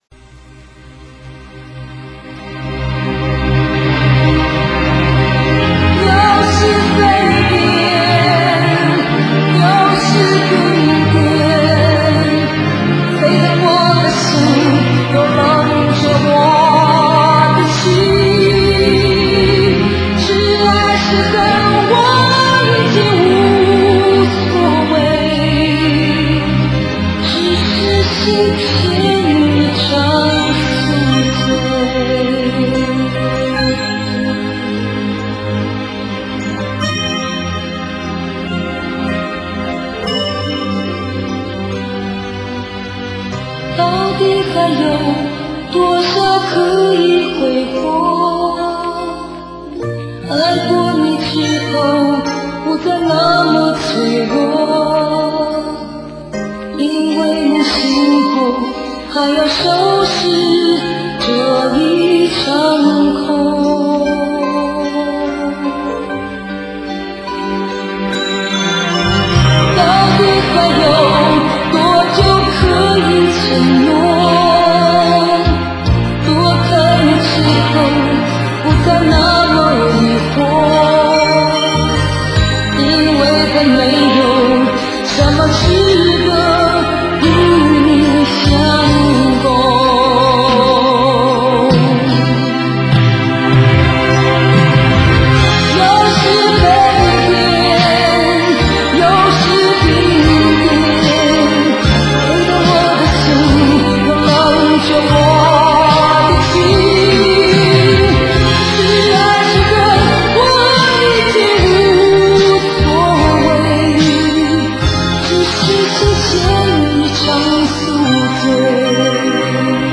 音质不是太好，先传上来听听：